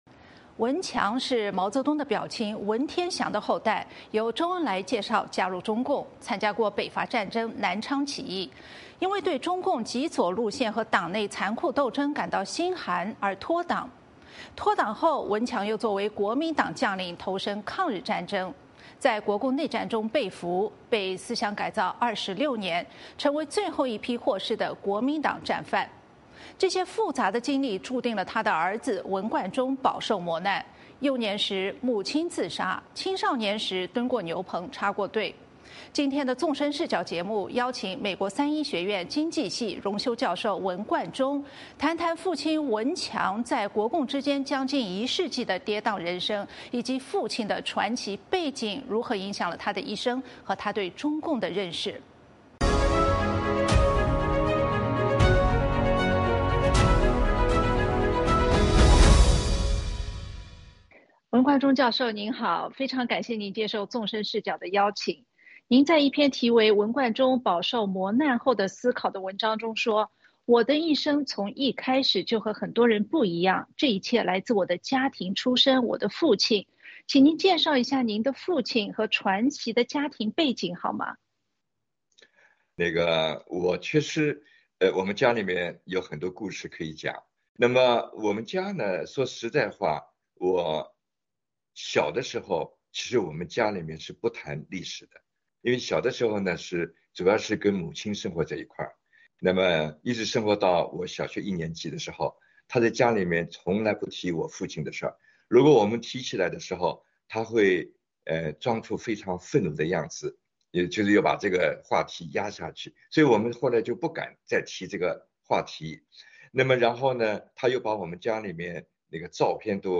《纵深视角》节目进行一系列人物专访，受访者所发表的评论不代表美国之音的立场